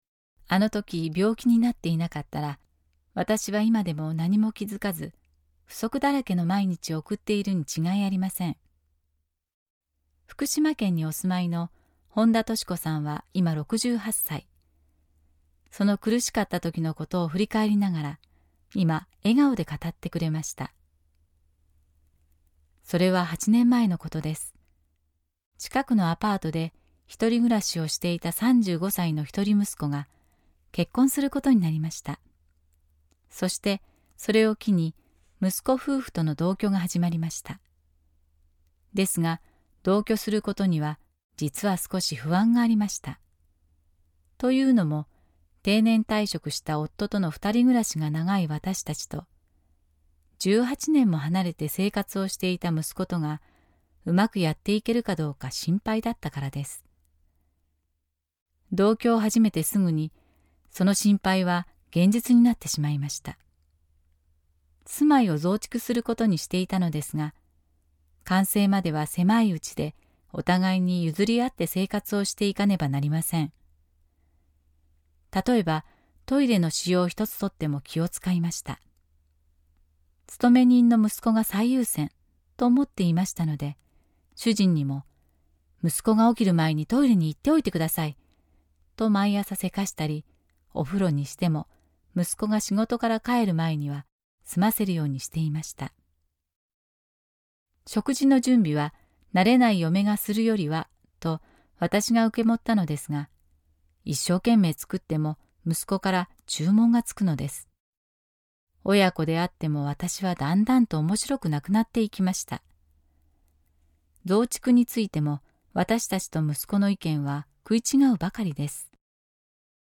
●信者さんのおはなし